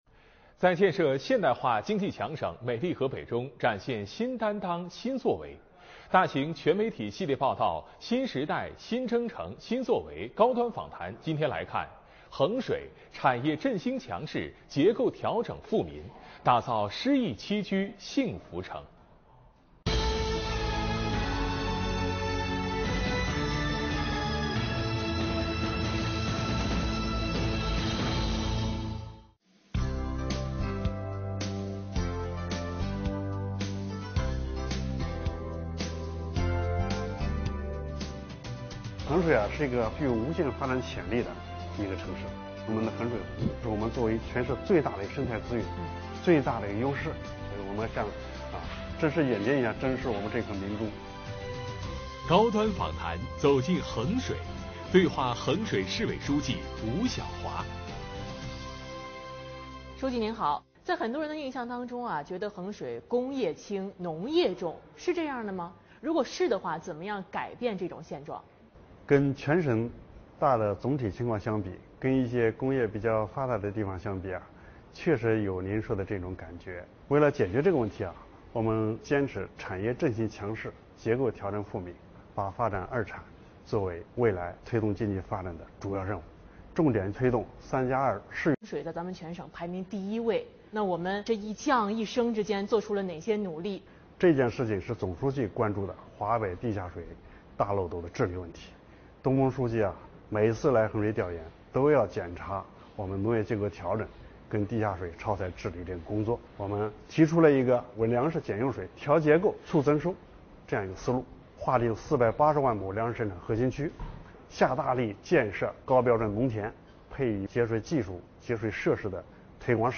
【河北新闻联播】新时代 新征程 新作为·高端访谈｜衡水：产业振兴强市 结构调整富民 打造诗意栖居幸福城